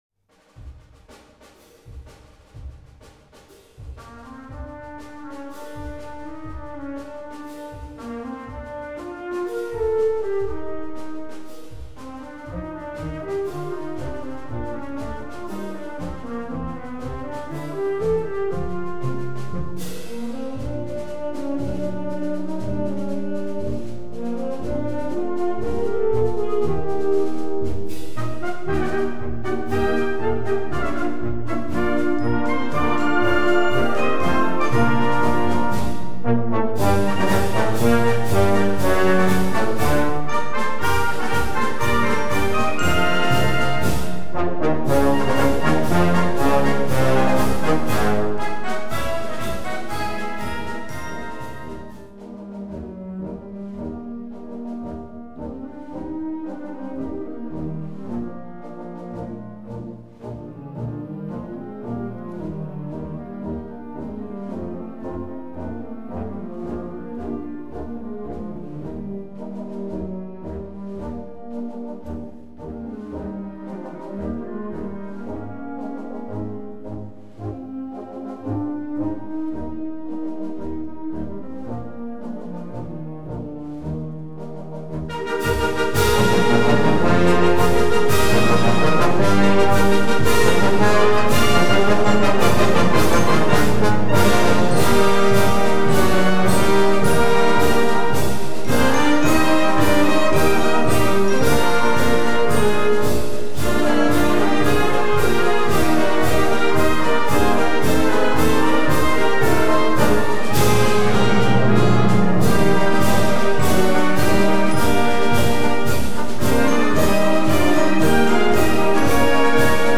Concert March, 4'25", Gr. 3, WB FA BB